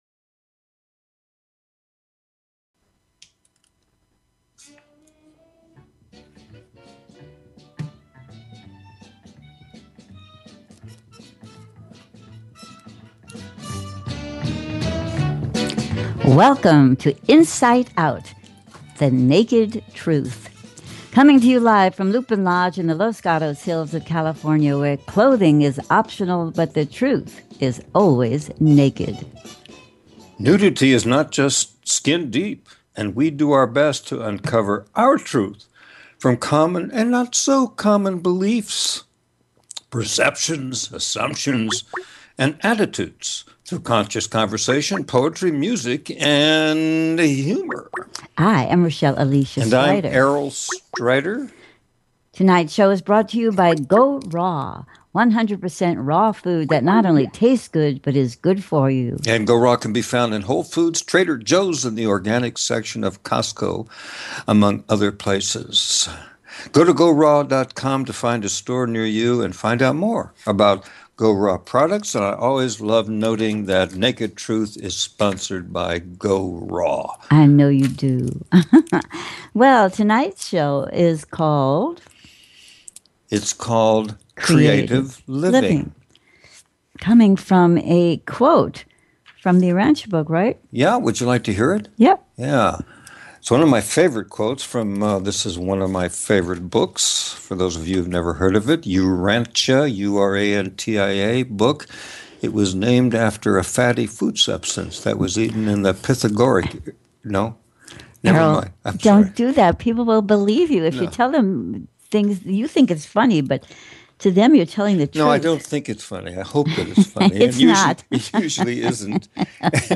Talk Show Episode
Featuring some wonderful songs and the unusual surprises that we love and look forward to.